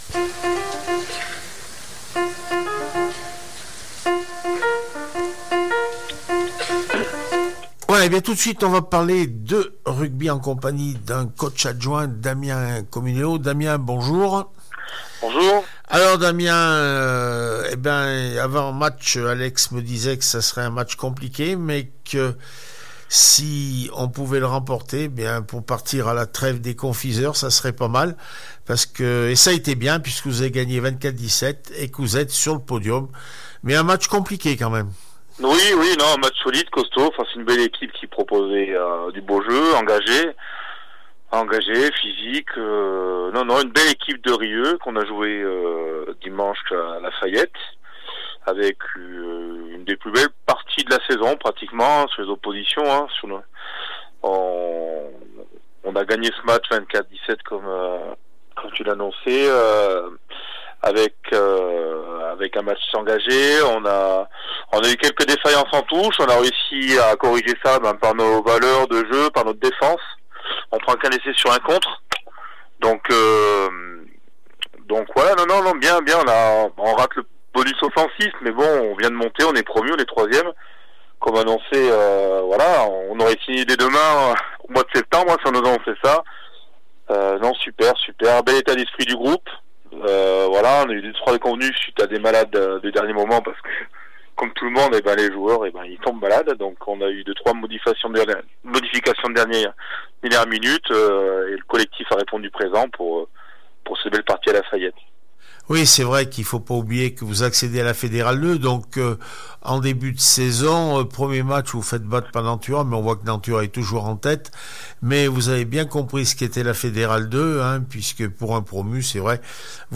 rugby fédérale 2 cop rugby 24-17 Rillieux la pape réaction après match